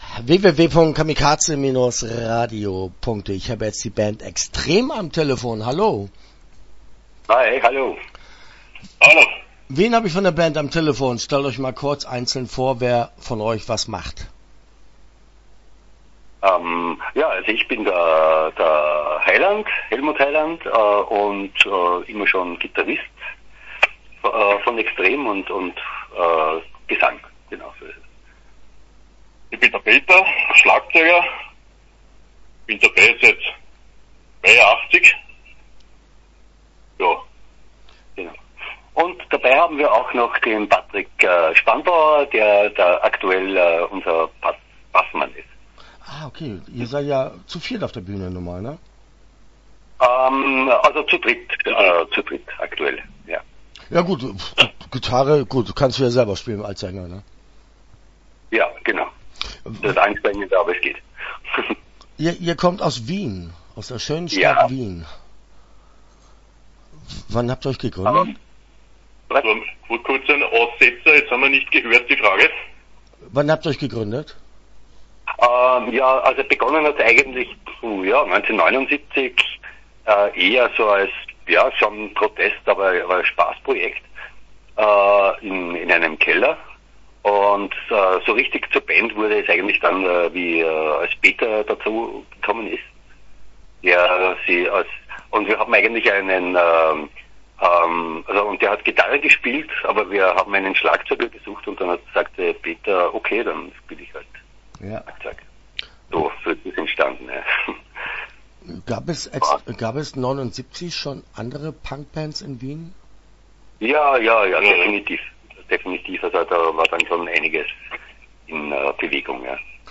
Extrem - Interview Teil 1 (10:50)